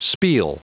Prononciation du mot spiel en anglais (fichier audio)
Prononciation du mot : spiel